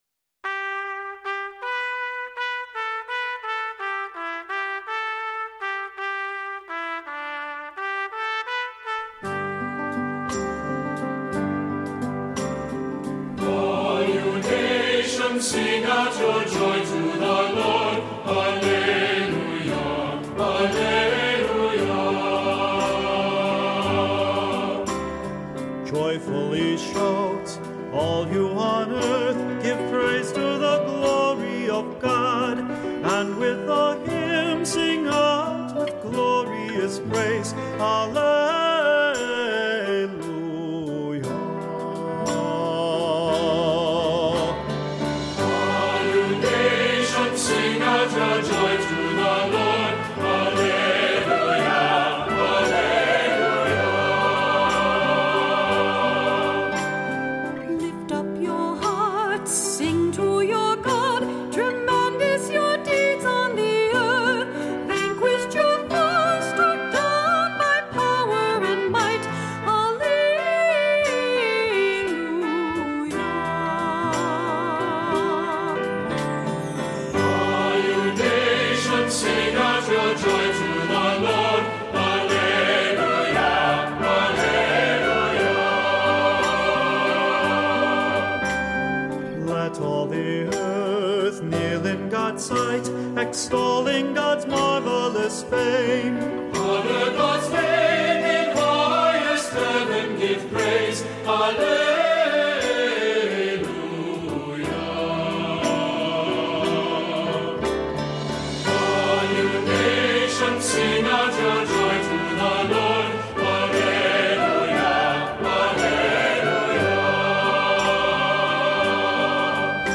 Voicing: "SATB", "Cantor", "Assembly"